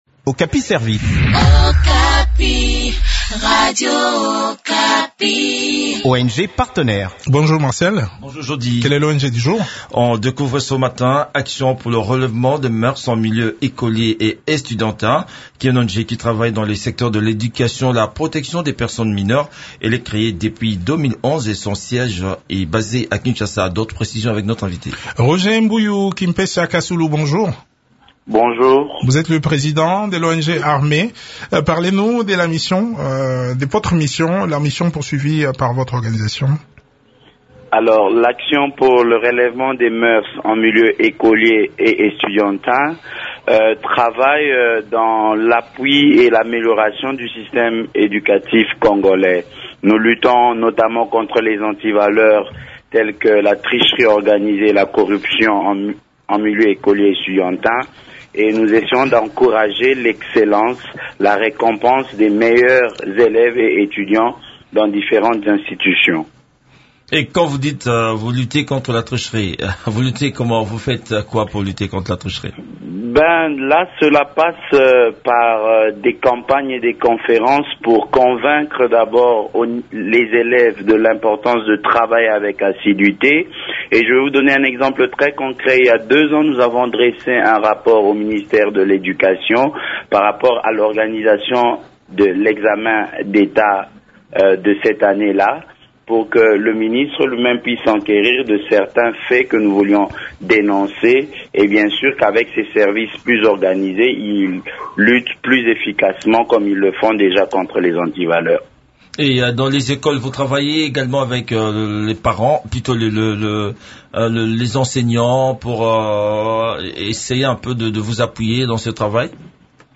font le point des activités de cette structure avec